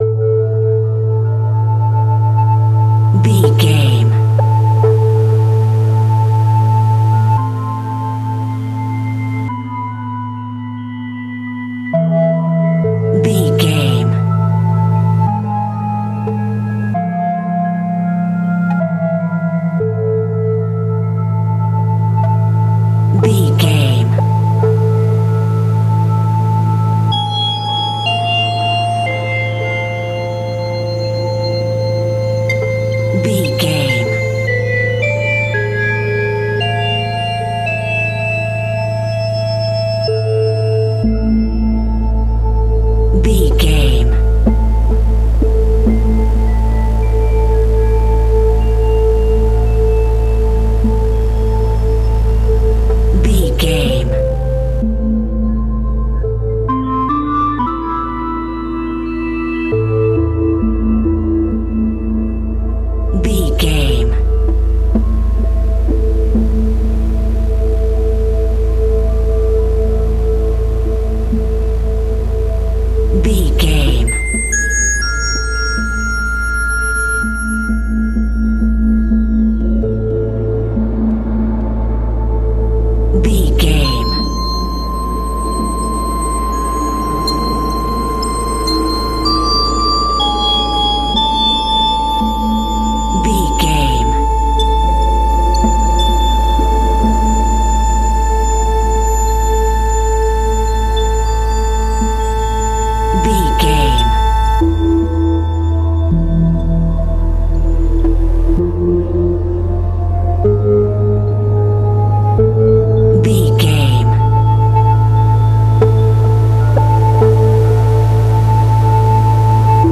Video Game Trailer
Aeolian/Minor
A♭
Slow
scary
tension
ominous
dark
suspense
dramatic
haunting
eerie
mysterious